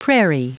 Prononciation du mot prairie en anglais (fichier audio)
Prononciation du mot : prairie